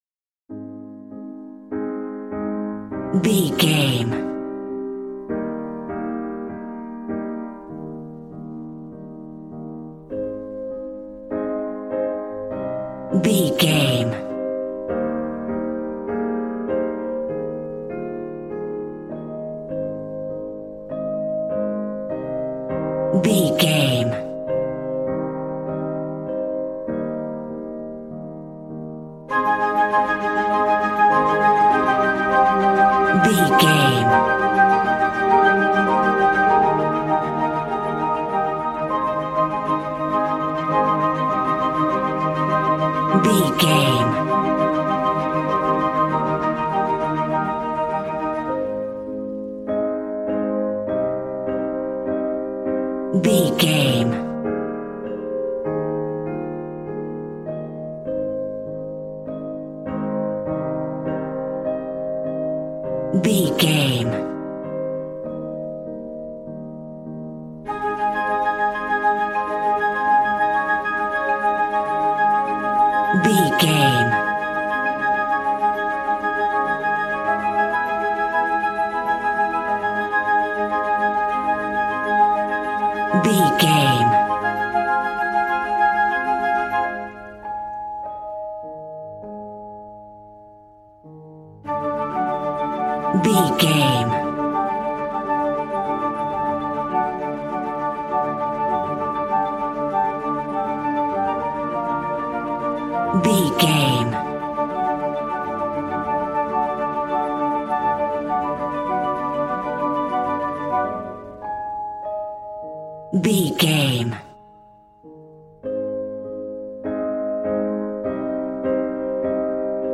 Regal and romantic, a classy piece of classical music.
Ionian/Major
A♭
regal
strings
violin
brass